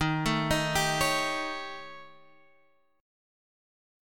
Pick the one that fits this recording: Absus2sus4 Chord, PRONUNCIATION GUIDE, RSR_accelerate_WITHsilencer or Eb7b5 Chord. Eb7b5 Chord